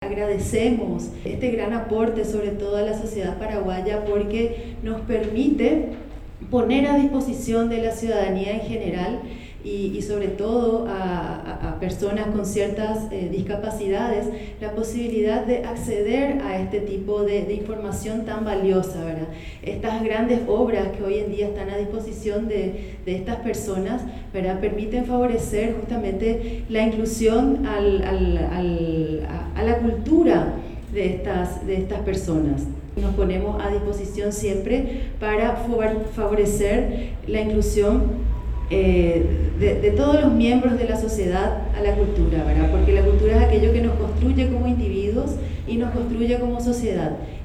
En un acto celebrado en el Sitio de la Memoria y Centro Cultural de Asunción, el Grupo Social ONCE presentó la iniciativa ‘Bibliotecas para todos’ cuyo objetivo es dotar a las bibliotecas nacionales de títulos en braille y en audio, así como de las tecnologías necesarias para el acceso a su lectura, ya sea digital, sonoro o ampliado, considerando la diversidad de formatos accesibles y los medios y modos de comunicación aumentativa y alternativa.